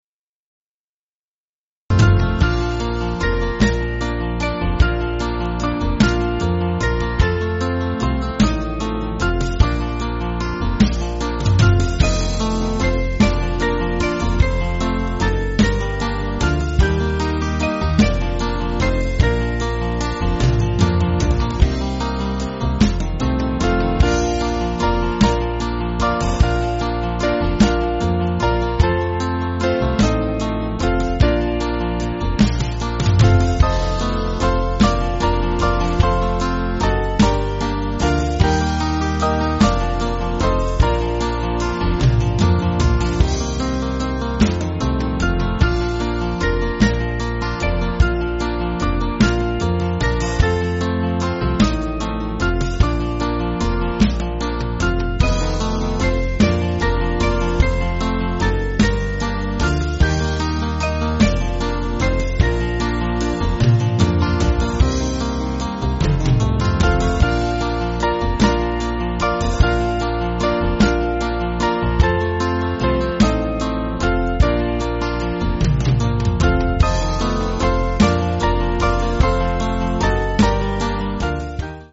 Small Band
(CM)   4/Bb